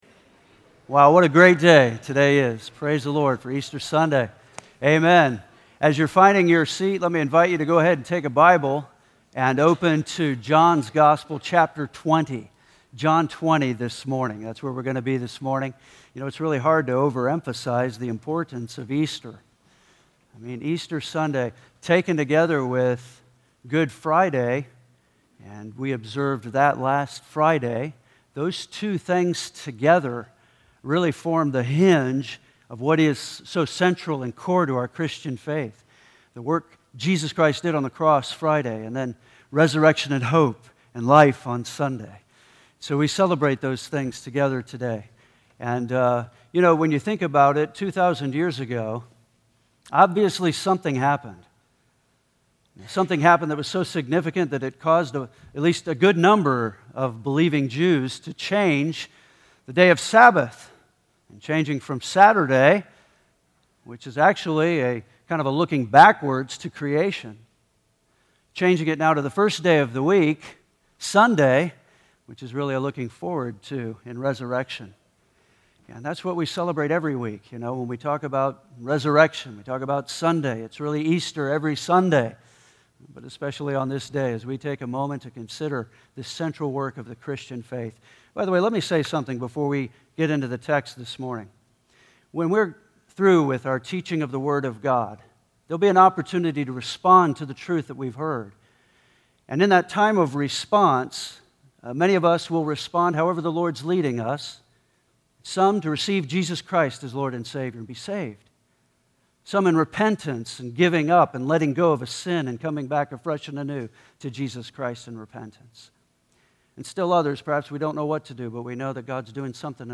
Easter Sunday